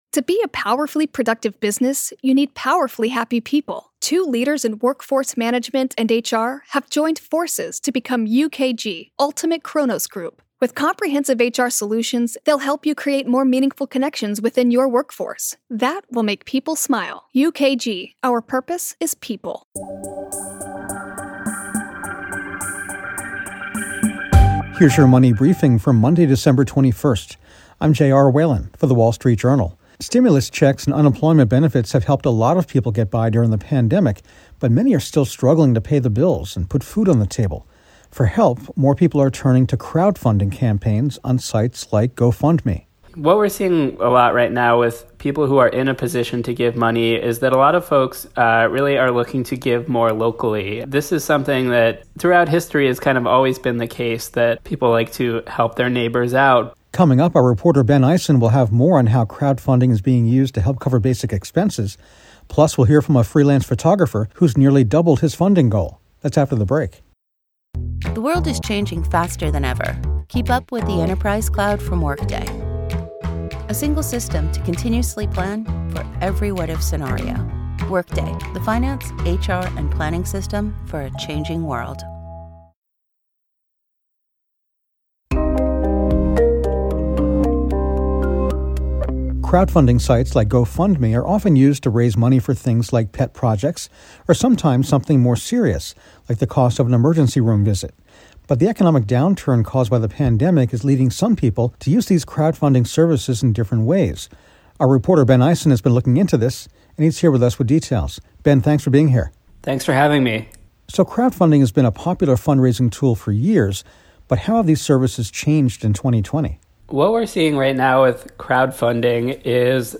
Plus, a freelance photographer discusses how his GoFundMe campaign has nearly doubled its goal.